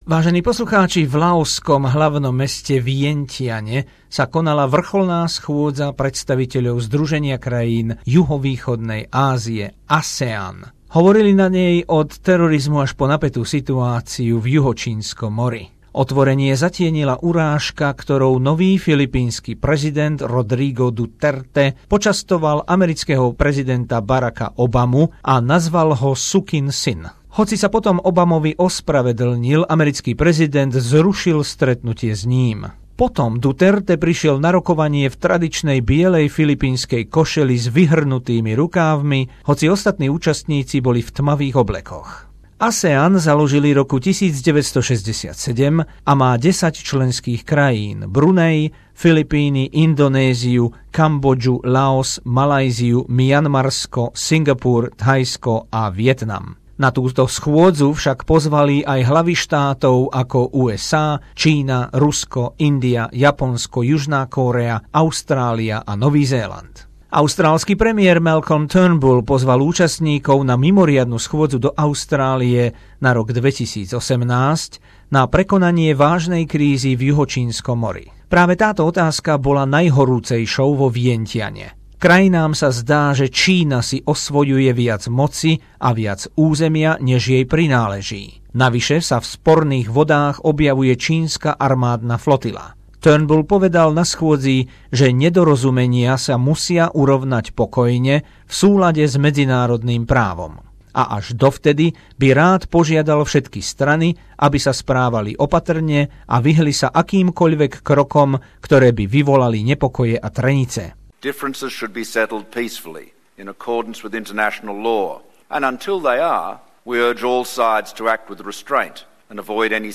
Rozšírená správa o vrcholnej schôdzi predstaviteľov krajín ASEAN v laoskom Vientiane za prítomnosti ďalších pozvaných krajín, pripravené zo spravodajskej dielne SBS